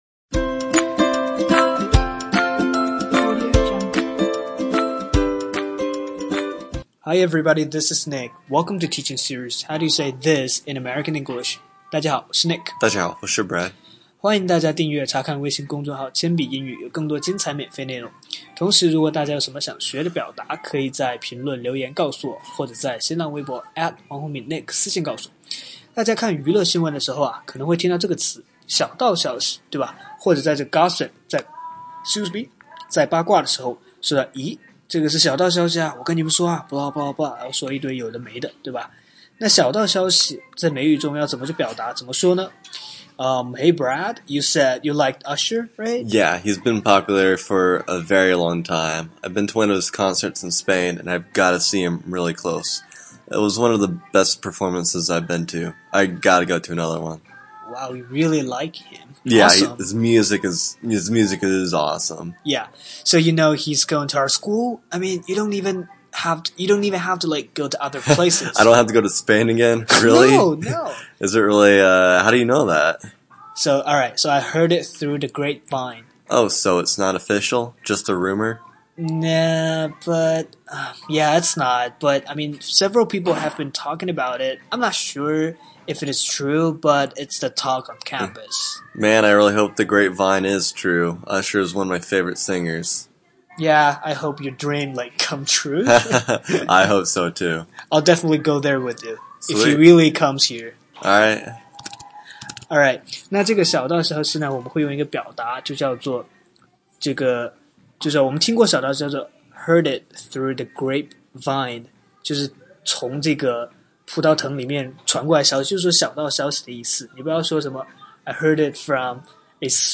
在线英语听力室全网最酷美语怎么说:第41期 小道消息的听力文件下载, 《全网最酷美语怎么说》栏目是一档中外教日播教学节目，致力于帮大家解决“就在嘴边却出不出口”的难题，摆脱中式英语，学习最IN最地道的表达。